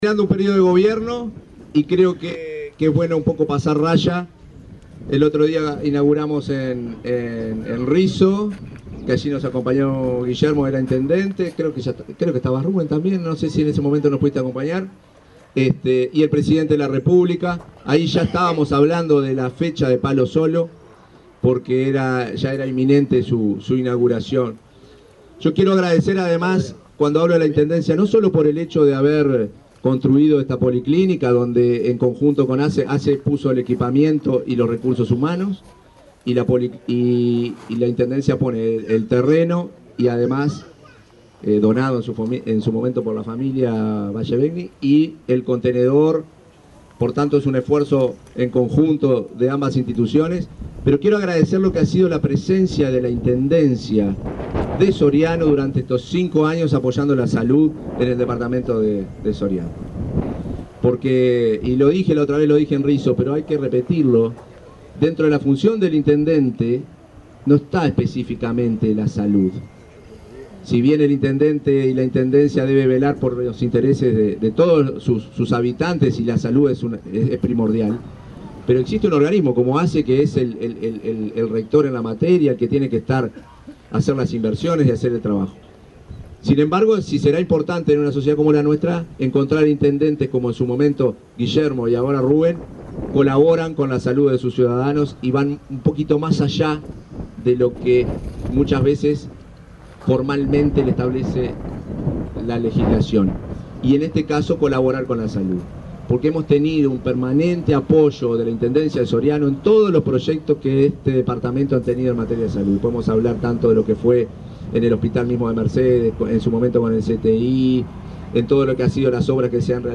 Palabras del presidente de ASSE, Marcelo Sosa
Este martes 25, el presidente de ASSE, Marcelo Sosa, encabezó el acto de inauguración de la policlínica de Palo Solo, en el departamento de Soriano.